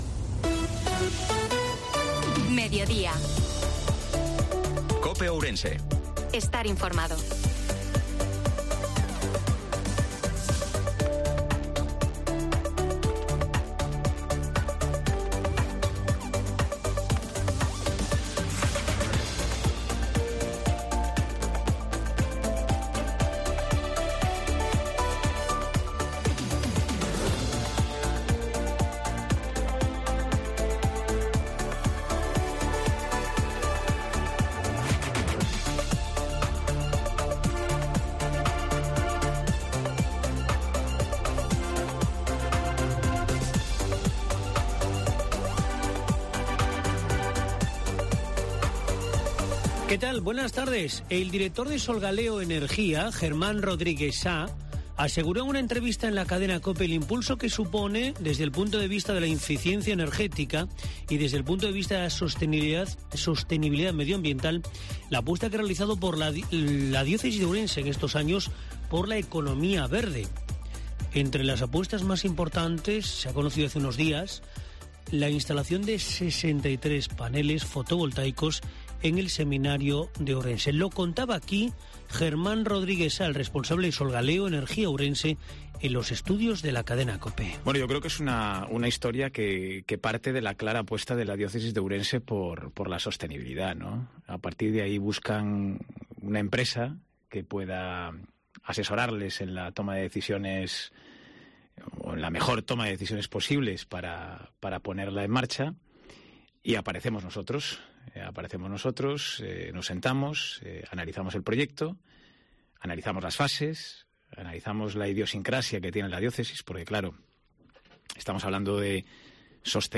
INFORMATIVO MEDIODIA COPE OURENSE-30/03/2023